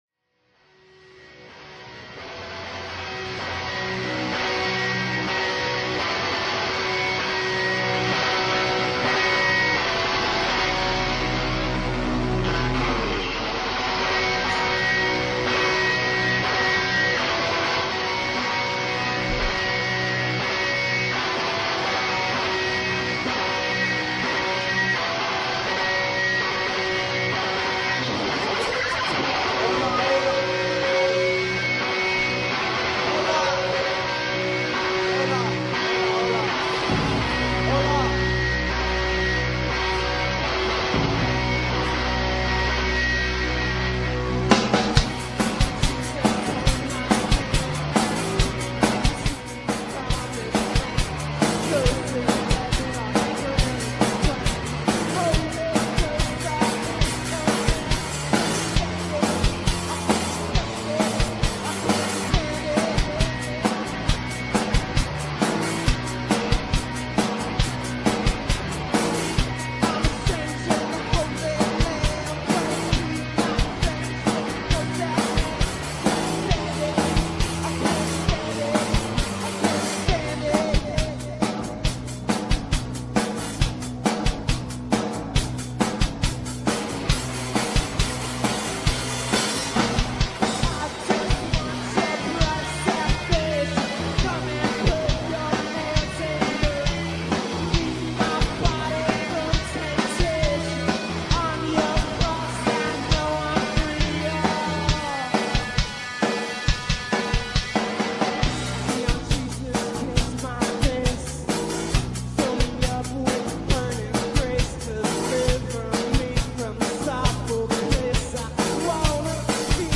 high-octane noise